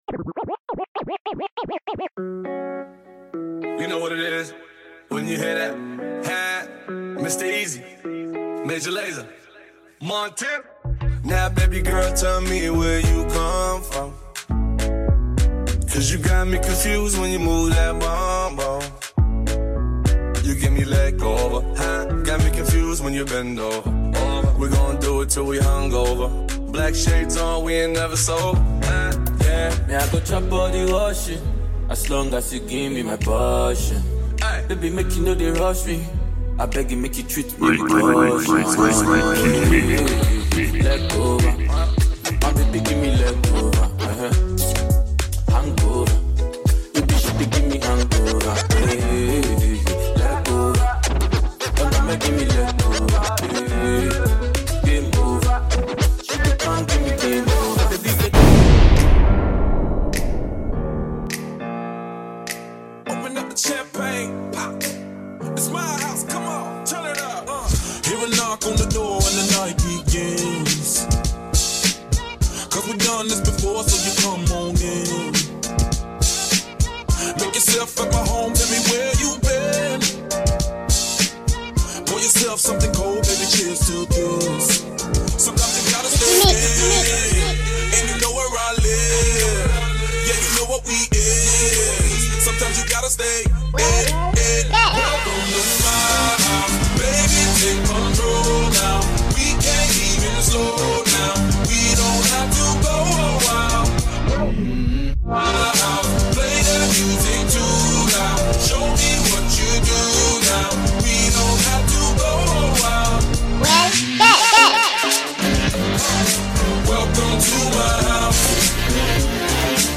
Genre : DJ MIXES